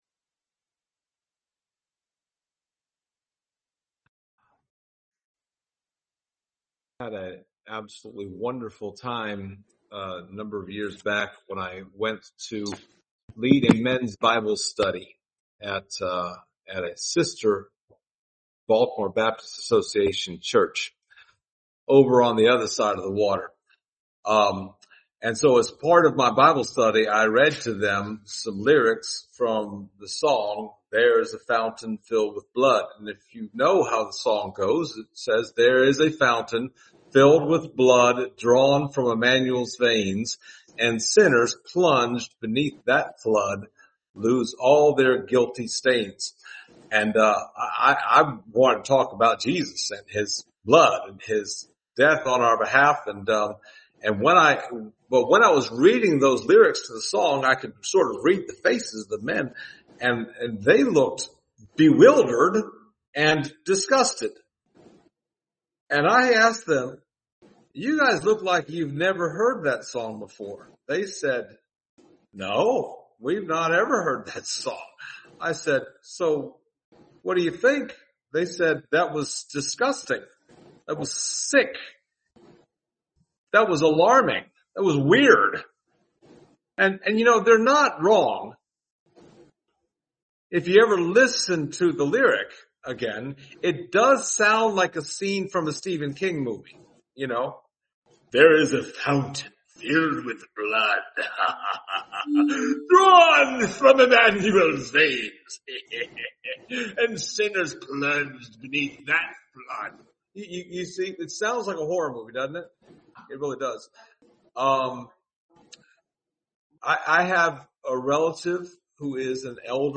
Service Type: Sunday Morning Topics: blood atonement